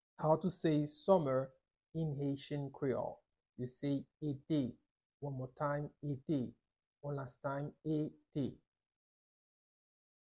26.How-to-say-Summer-in-Haitian-creole-ete-with-pronunciation.-1.mp3